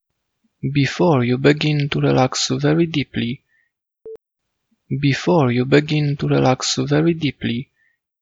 Sound like a "click" in my voice recordings [SOLVED]
There are strange sound “click” beetween 1,5-2 sec (red color) exactly like I say.
I’m confident those clicks are produced by your mouth, saying the plosives “g” “t” & “d” , rather than artefacts created by electronics or software.